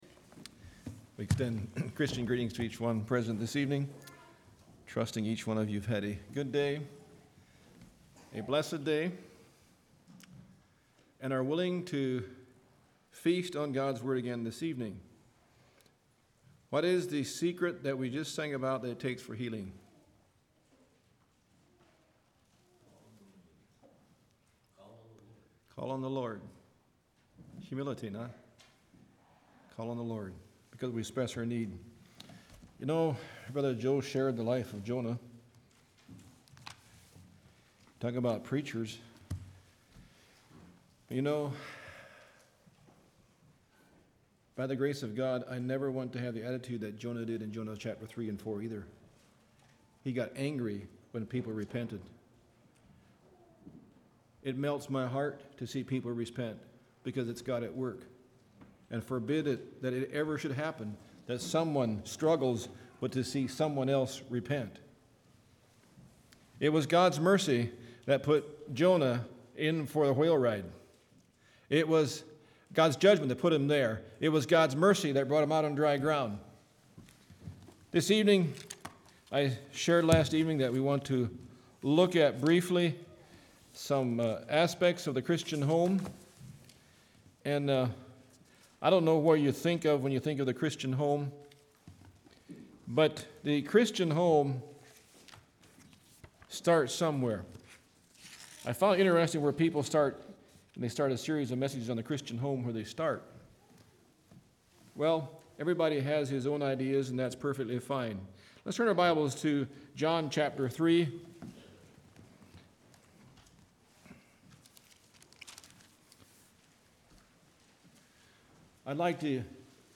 Congregation: Mount Joy
Sermon